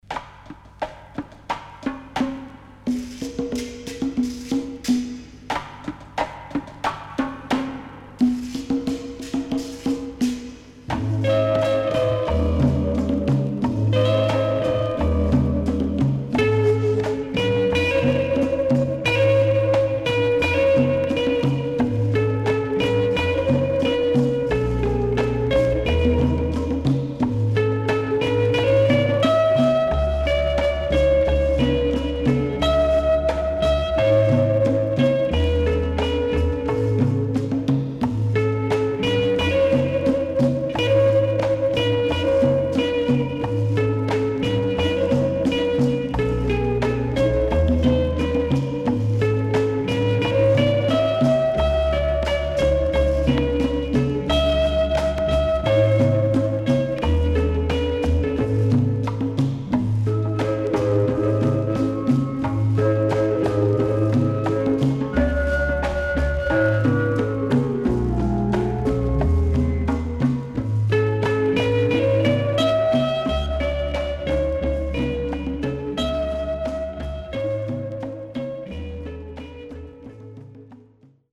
Caribbean Inst LP
SIDE B:少しノイズ入りますが良好です。